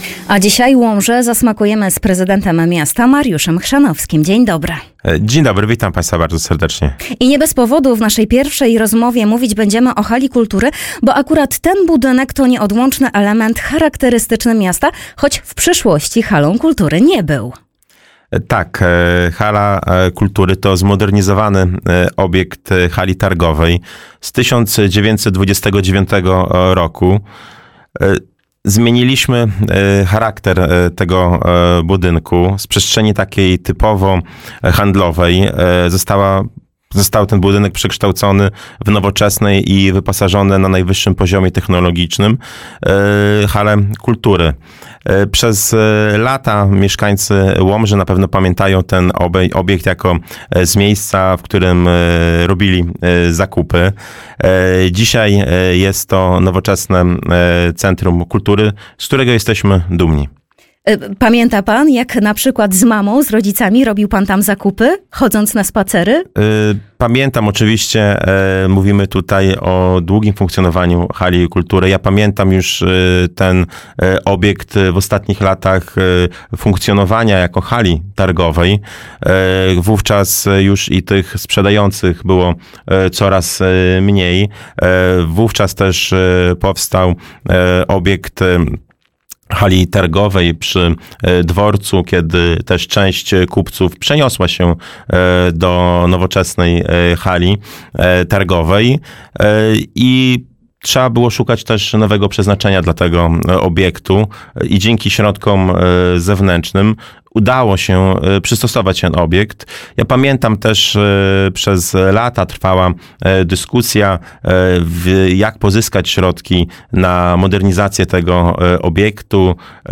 Gościem pierwszej audycji był Prezydent Miasta Łomża – Mariusz Chrzanowski.